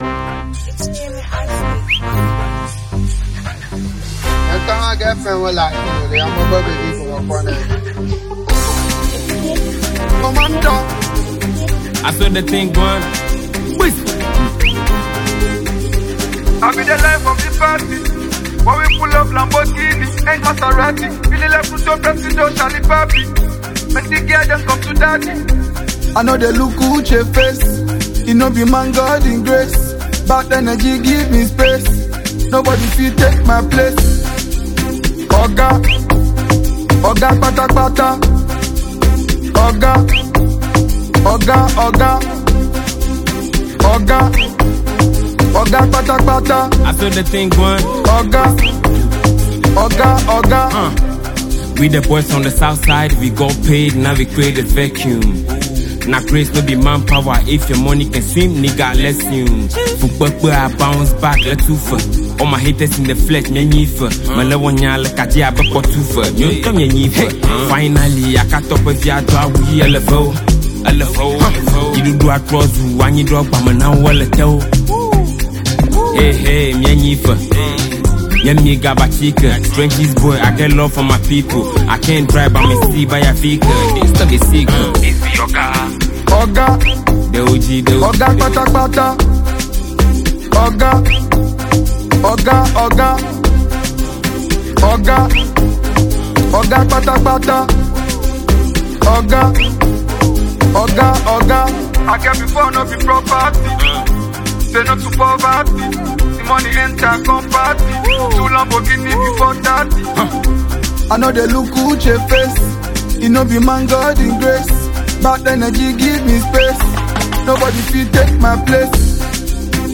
with catchy beats and impressive lyrics